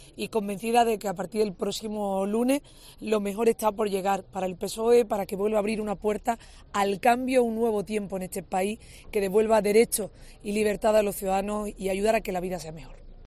Ha sido en una comparecencia sin preguntas a los medios en la sede de Ferraz, con un semblante muy serio, arropada por una veintena de miembros de su candidatura y en la que no ha mencionado en ningún momento a Sánchez.